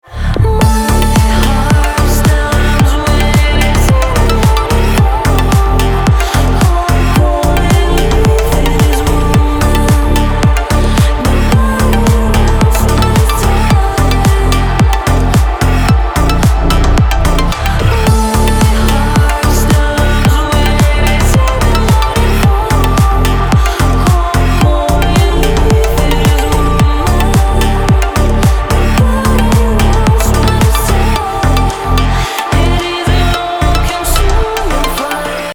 • Качество: 320, Stereo
deep house
атмосферные
progressive house
крутой припев